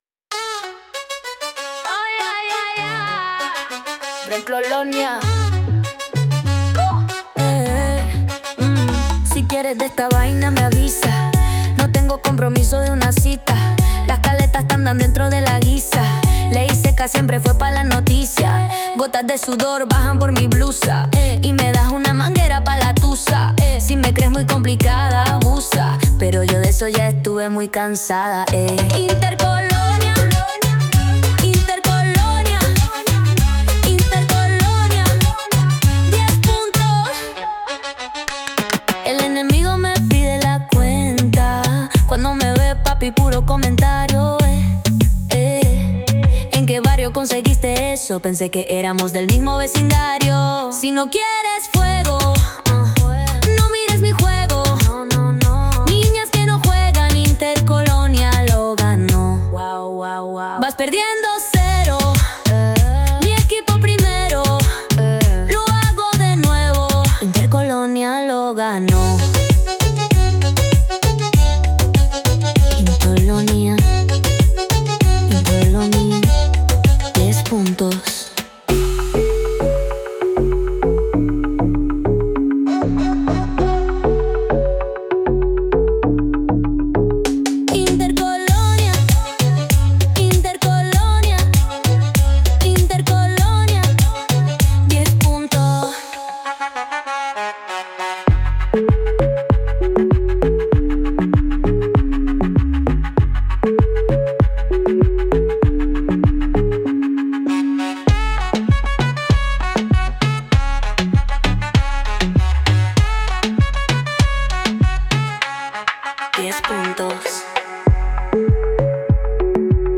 Mijn laatste probeersel is een liedje gegenereerd door suno.
Tsja, het klinkt onvoorstelbaar goed.
INTER-COLONIA-reggaeton.mp3